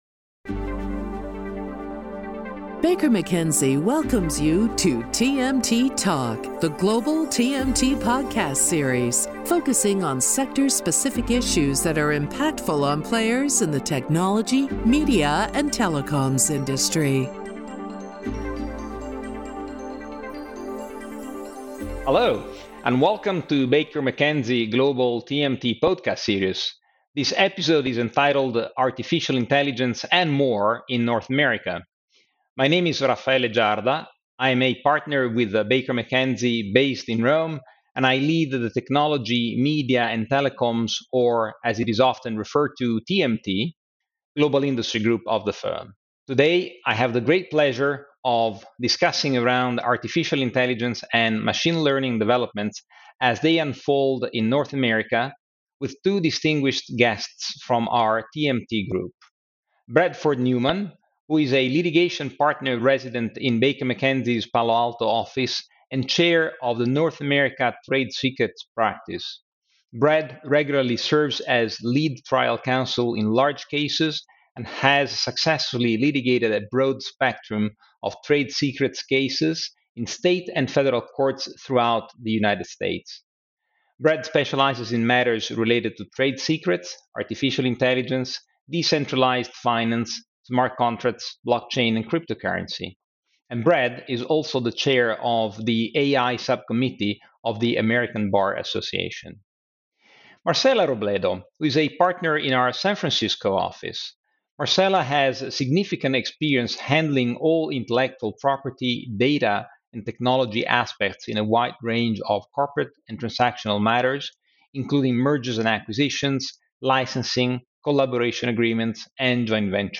In this episode of TMT Talk, our panel of experts look into the complexity of AI/ML, and give their perspective on specific trends